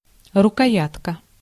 Ääntäminen
IPA : /hɪlt/